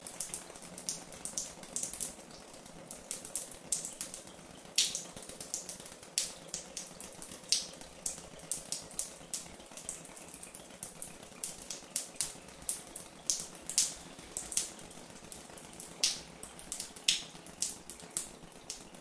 Пот льется литрами (имитация)